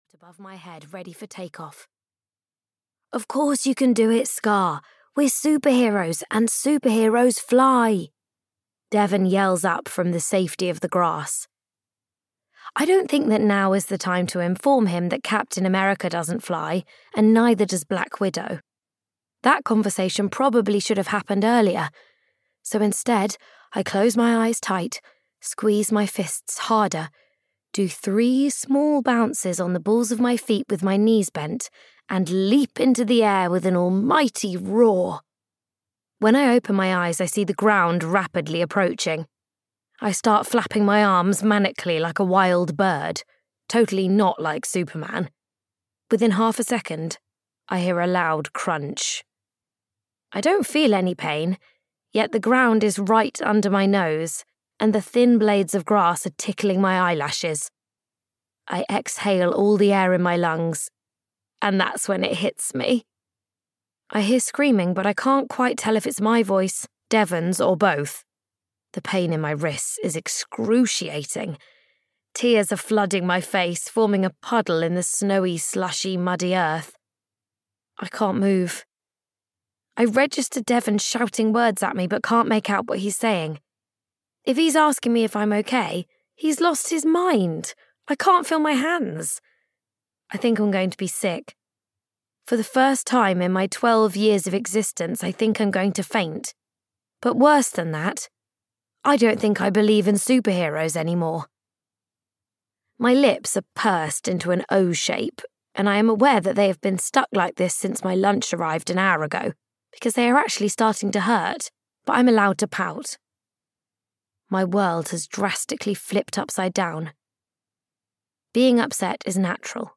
One Snowy Week in Springhollow (EN) audiokniha
Ukázka z knihy